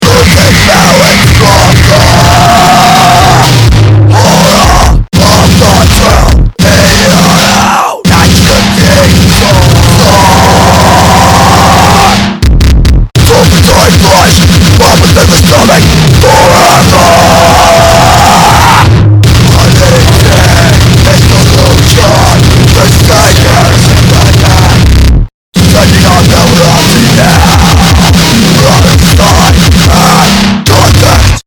Call it cybergrind, digital hardcore, or power electronics